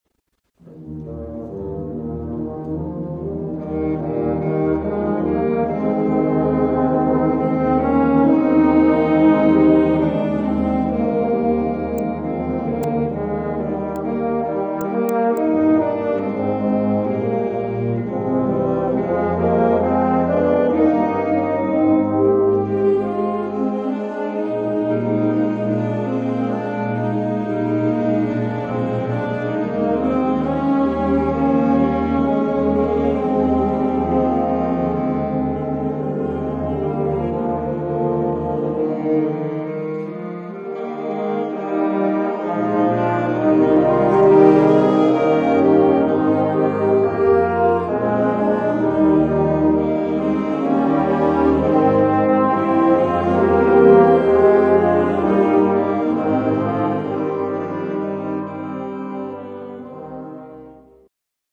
Gattung: Choralkantate
Besetzung: Blasorchester